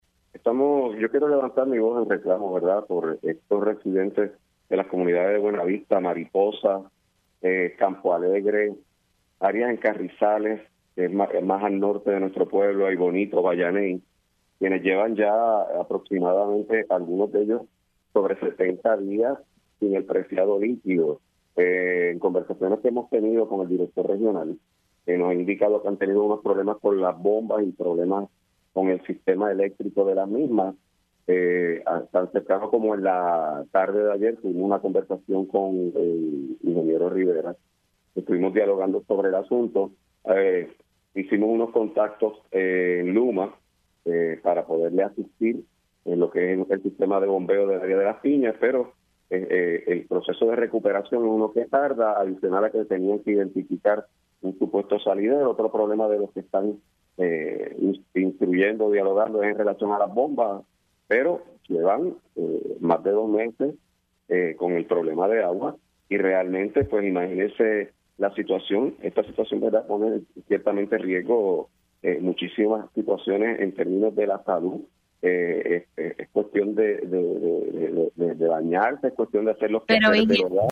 El alcalde de Hatillo, Carlos Román denunció en Pega’os en la Mañana que varias zonas en su municipio – incluyendo Buena Vista, Mariposa, Campo Alegre, Carrizales, Aibonito y Bayané – tienen más de 70 días sin agua potable.